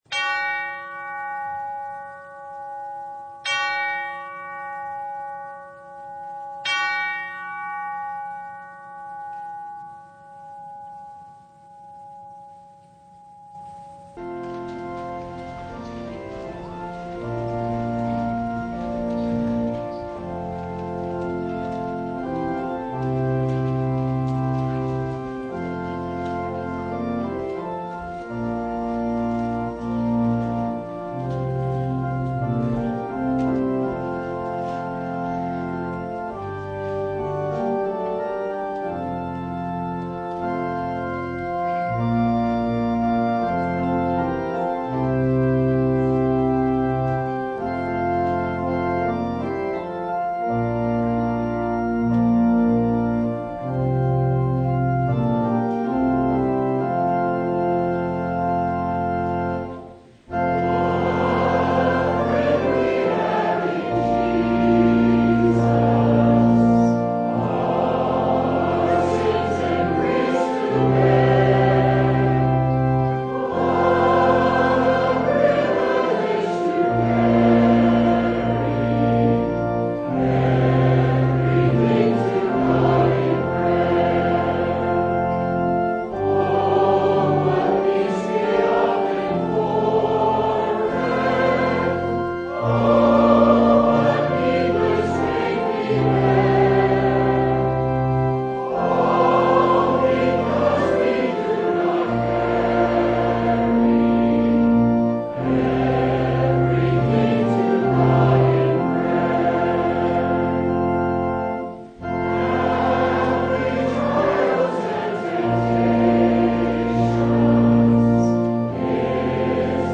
Matthew 4:12-25 Service Type: Sunday The Lord sent a champion with His light into the lands of darkness.